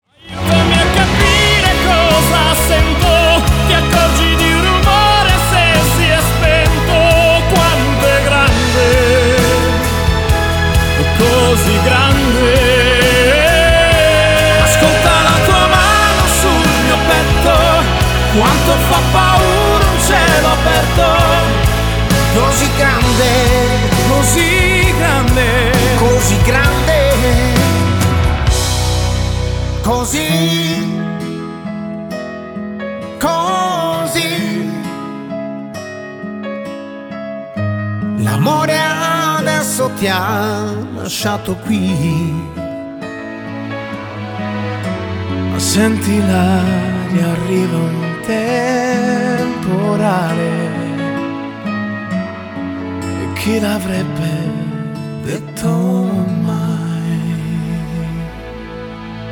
• Качество: 320, Stereo
поп
мужской вокал
громкие
грустные
медленные
красивый мужской вокал
романтичные
медлячок
печальные